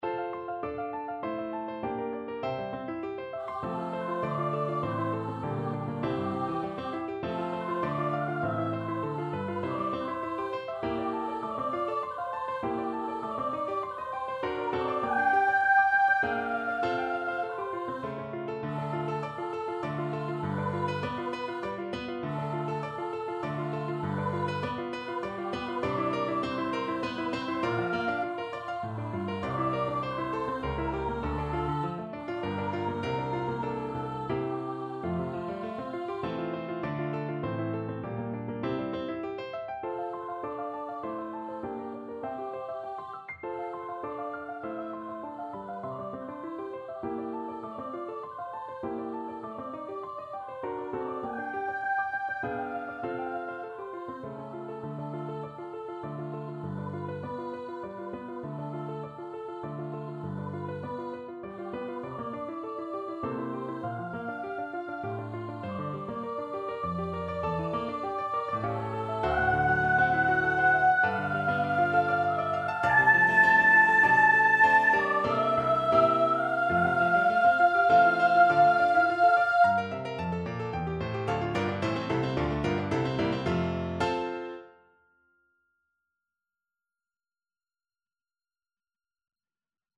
~ = 100 Very quick and passionate
3/4 (View more 3/4 Music)
D5-A6
Classical (View more Classical Soprano Voice Music)